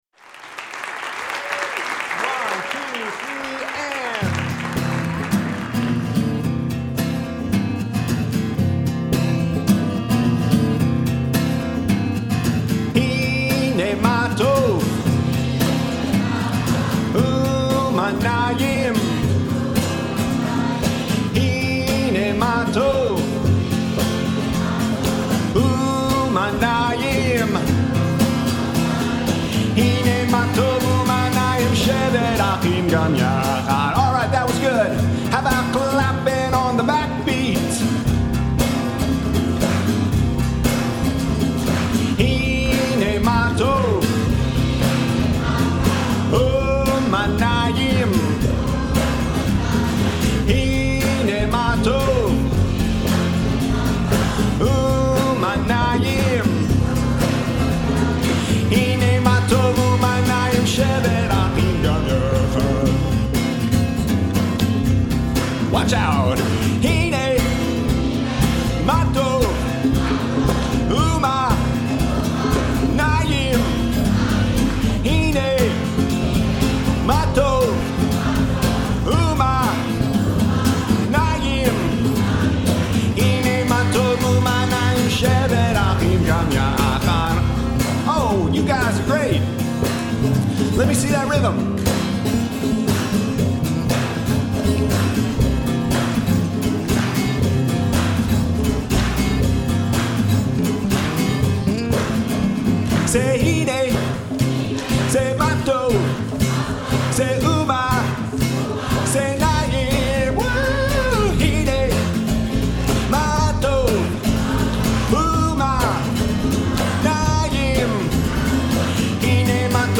in concert